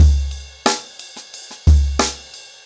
InDaHouse-90BPM.11.wav